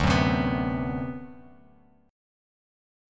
D13 Chord
Listen to D13 strummed